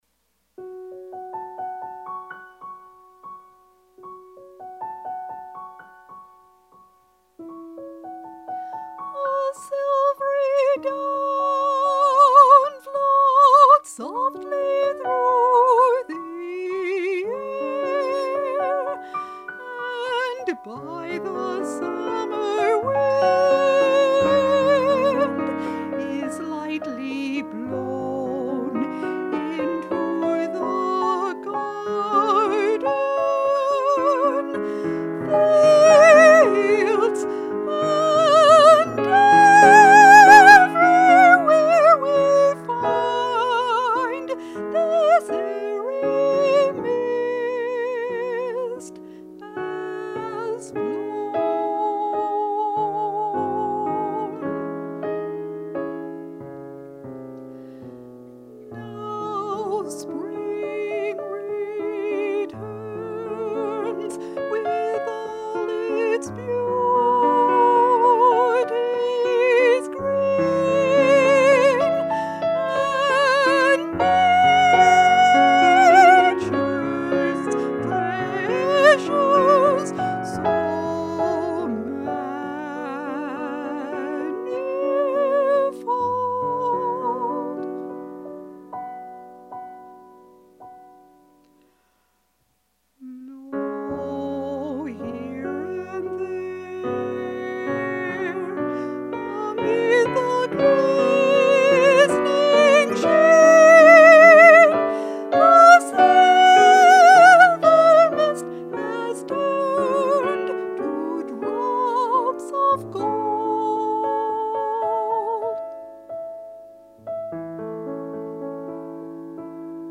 voice and piano